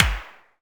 clapOnbeat5.ogg